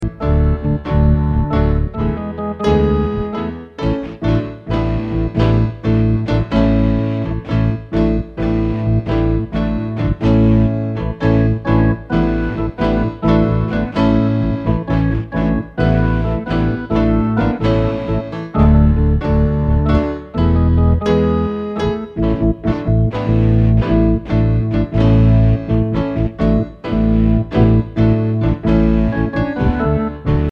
Listen to the instrumental backup track.